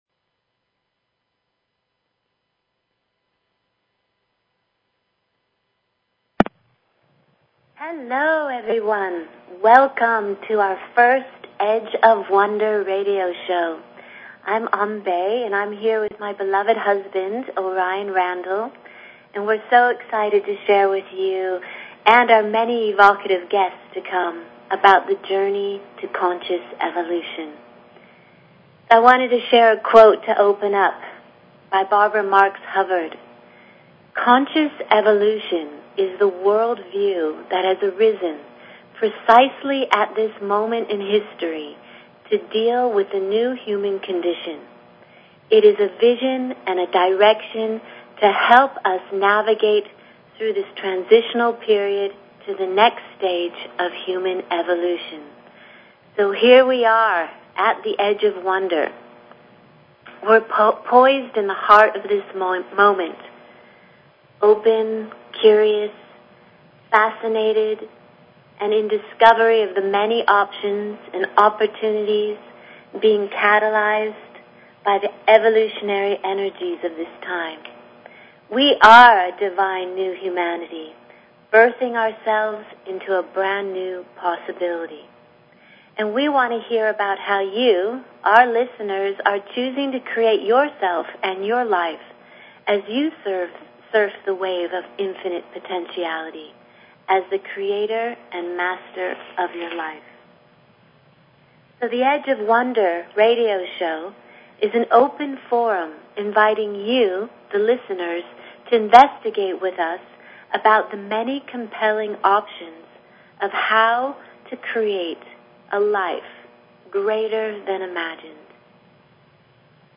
Talk Show Episode, Audio Podcast, Edge_of_Wonder_Radio and Courtesy of BBS Radio on , show guests , about , categorized as